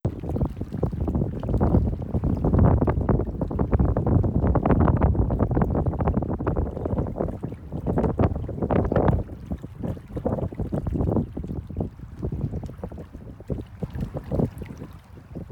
I was also considering at this time the ambient sound from Tryweryn and created the following piece using recordings of the wind and water and incorporating a fade in/fade out.
wind-and-water-fade-in-and-out.wav